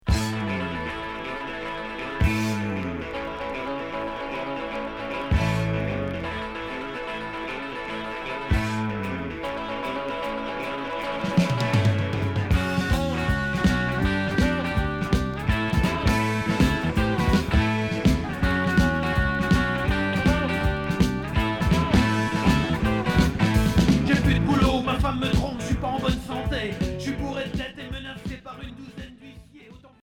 Rock boogie